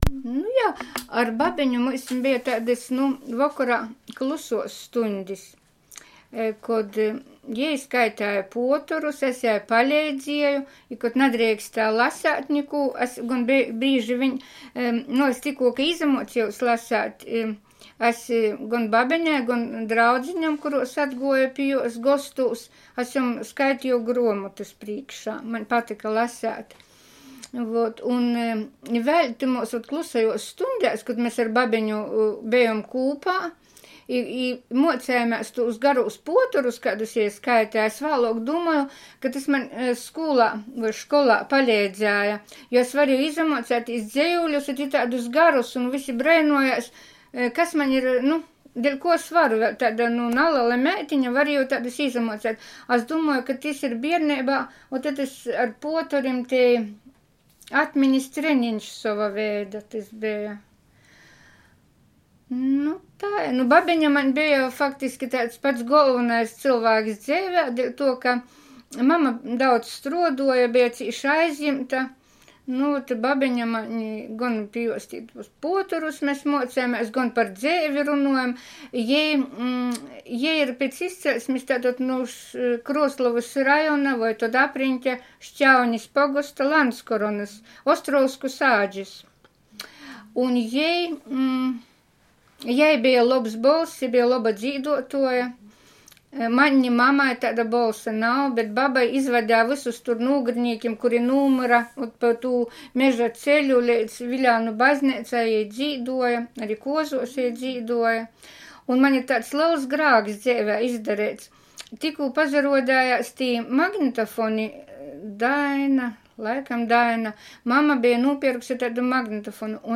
nagrane w domu mówcy